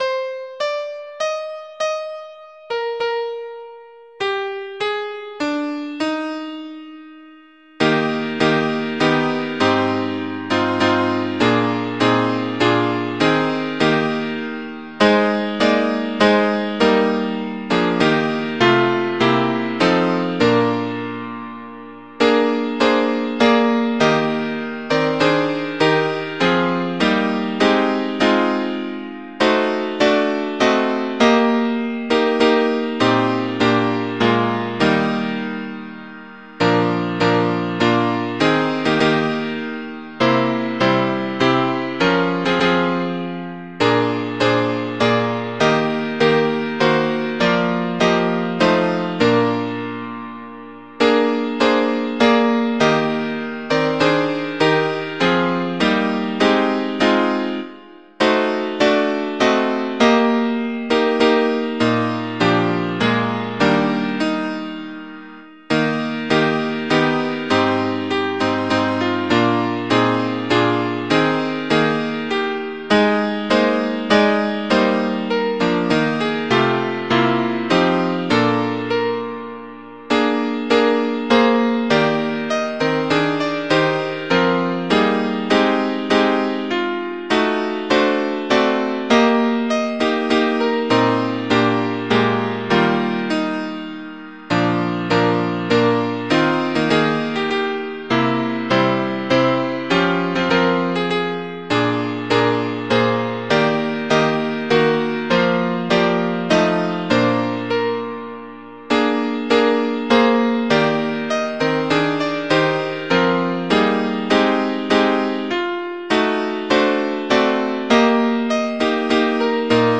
Mp3 Audio of Tune Abc source